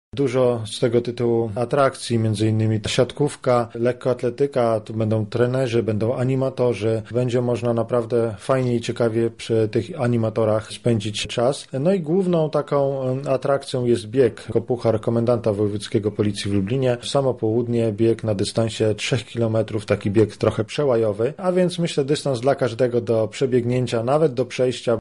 O atrakcjach festynu mówi komisarz Komendy Wojewódzkiej Policji